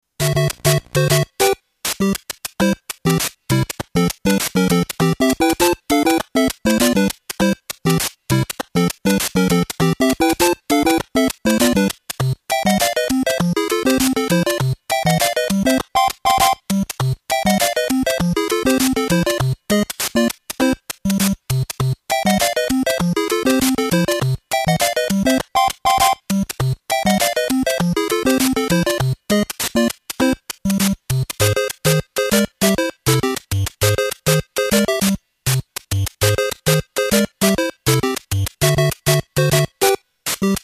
8 bit
Отличного качества, без посторонних шумов.